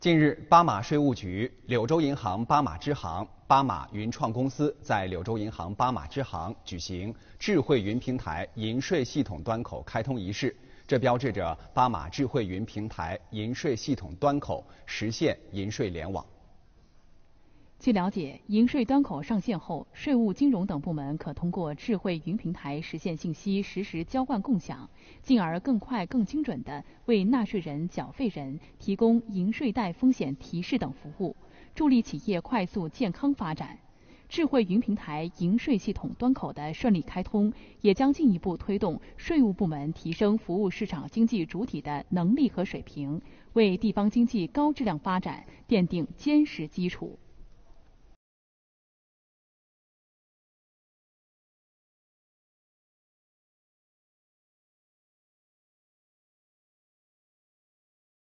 电视报道 | 巴马“智慧云平台”银税系统端口正式上线